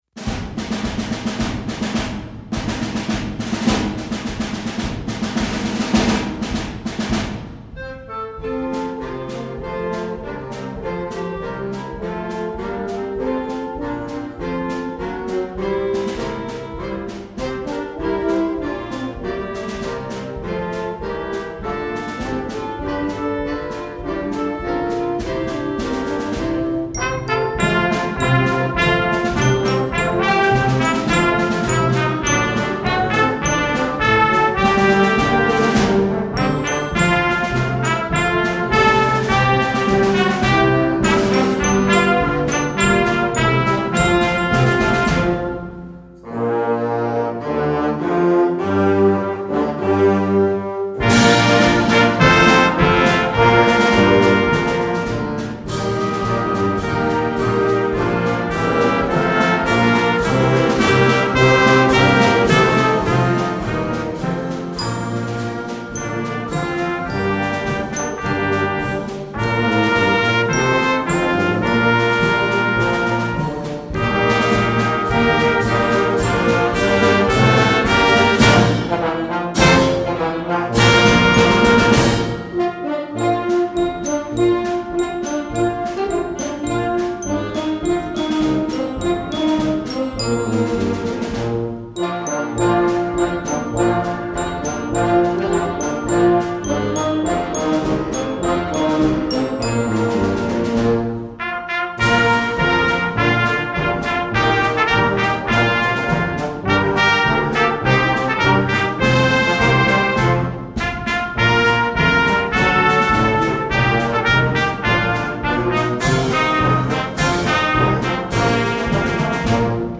Concert Band
A patriotic medley